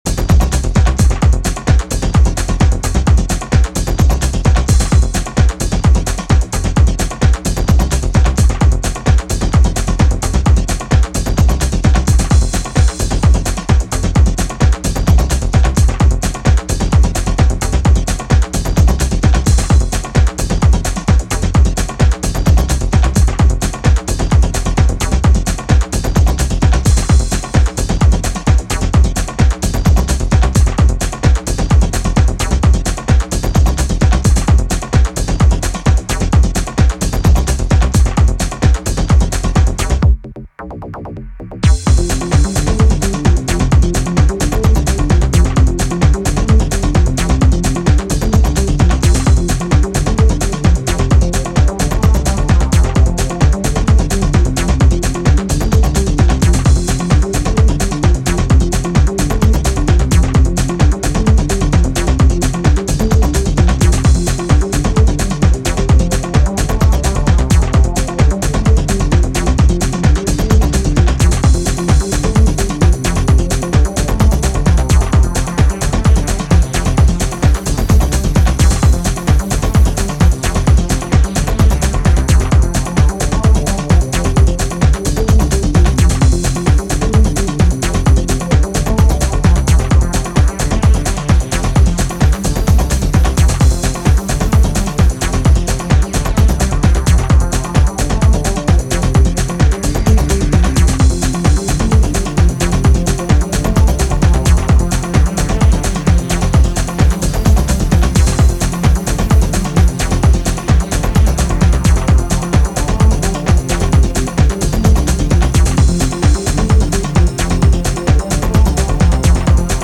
ファンキーなブレイクビーツ、よりアップリフティングなアルペジオでギアを上げる